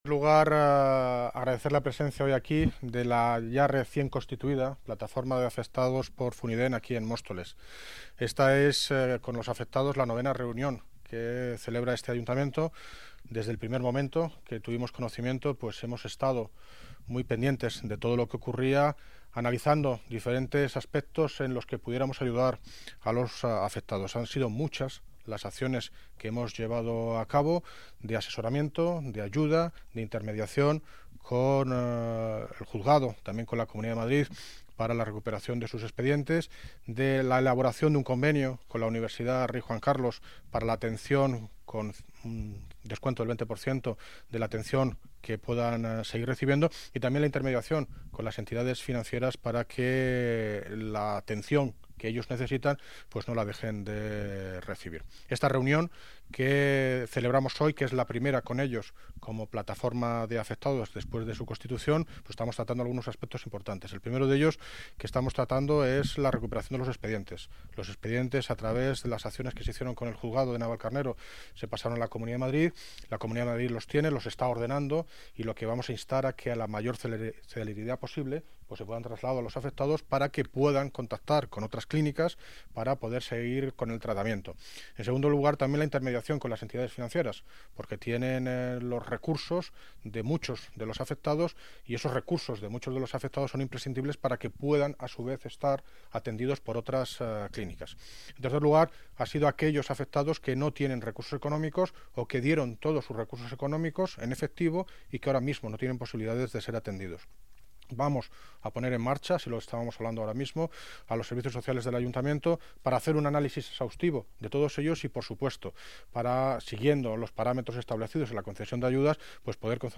Audio - David Lucas (Alcalde de Móstoles) Sobre Afectados FUNYDENT